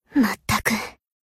BA_V_Shiroko_Battle_Damage_2.ogg